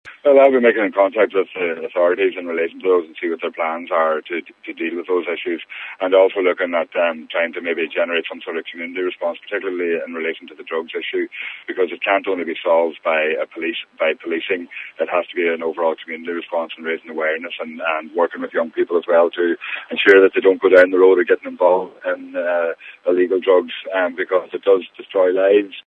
Deputy Pringle says a community and policing response is required in addressing concerns in the Finn Valley: